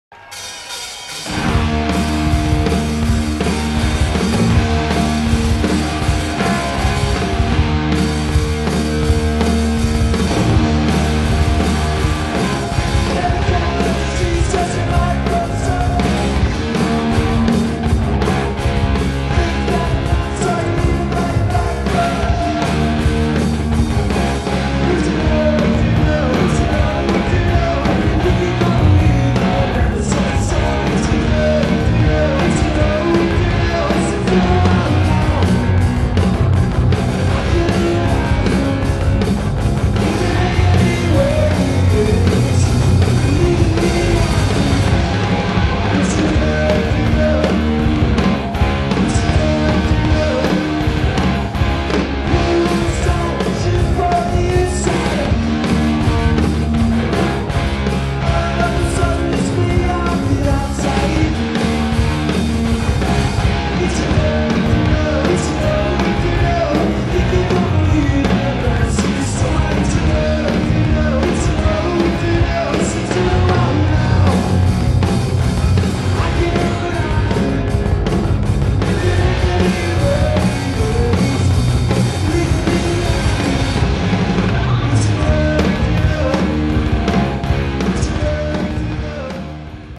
Parramatta Leagues Club (Late 1980)